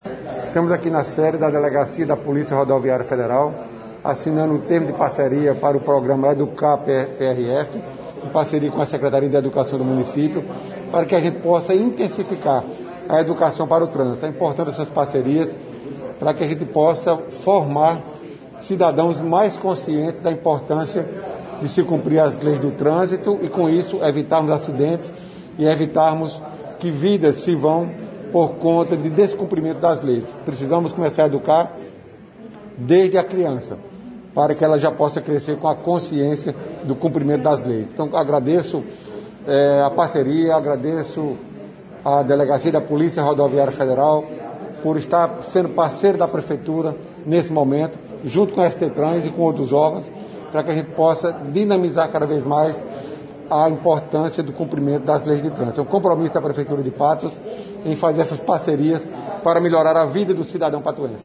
Aconteceu na manhã desta sexta-feira (26) na sede da Delegacia da PRF Patos a assinatura de um Termo de Adesão e Compromisso entre a Prefeitura de Patos, Polícia Rodoviária Federal e Secretaria Municipal de Educação, ao Projeto EDUCAR PRF.